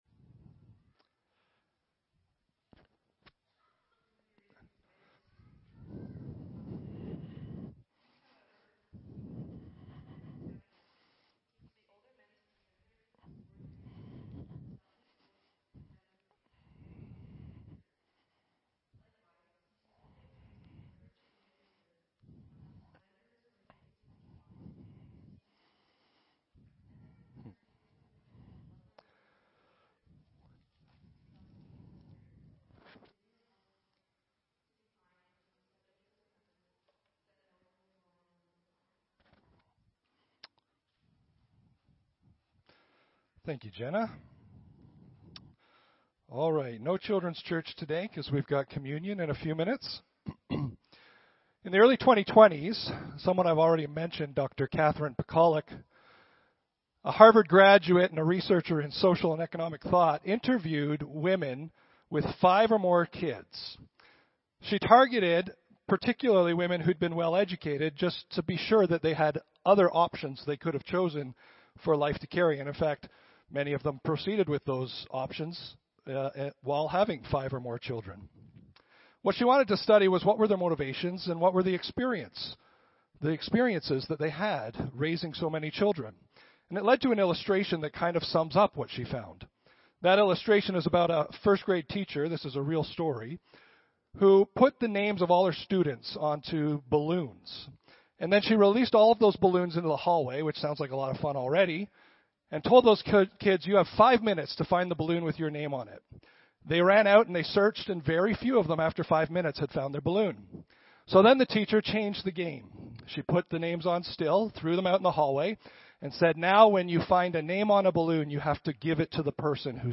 Audio Sermon Library